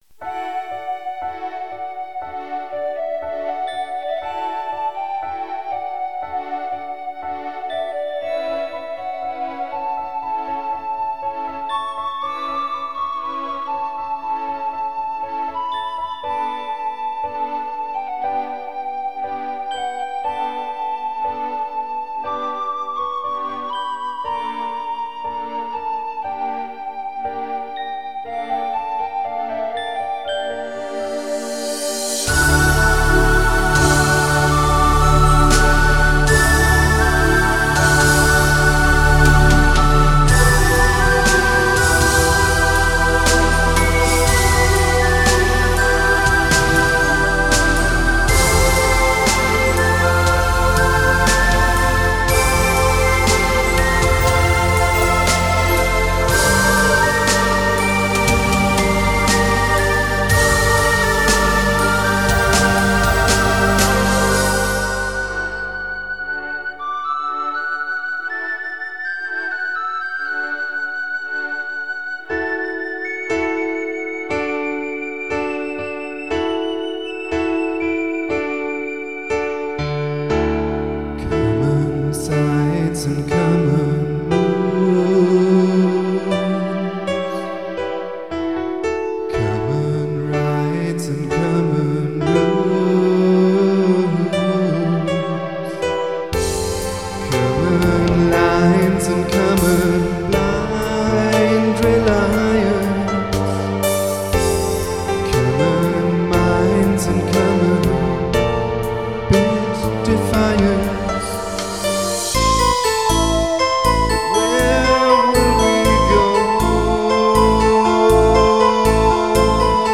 Eine Bombast-Ballade aus dem Jahr 1996 - der Gesang ist wie alles aus dieser Zeit live, während ich das Ganze gleichzeitig live abmischte.